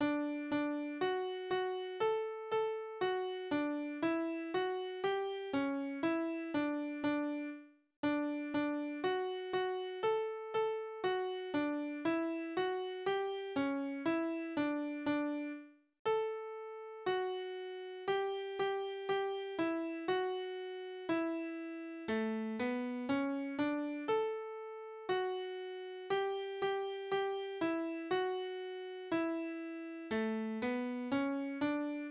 Tanzverse: Ecossaise
Tonart: D-Dur
Taktart: 4/4
Tonumfang: Oktave
Besetzung: vokal